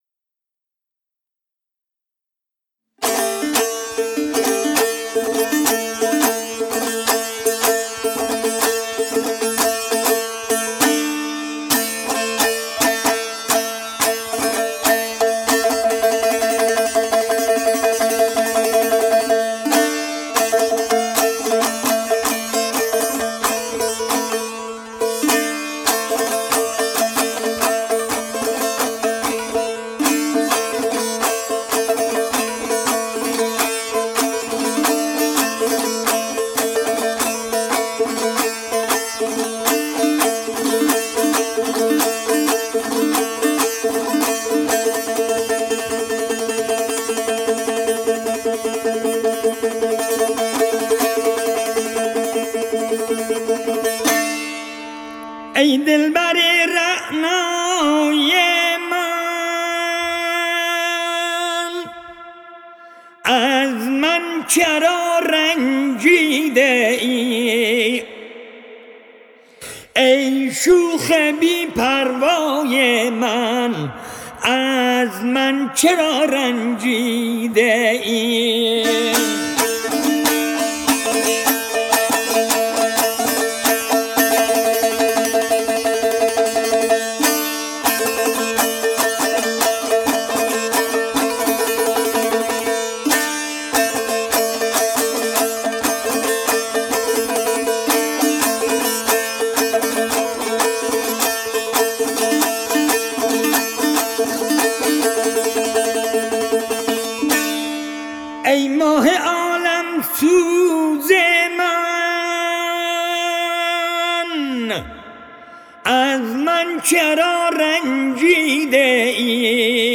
موسیقی مقامی خراسان تکنوازی دوتار آواز غلامعلی پورعطایی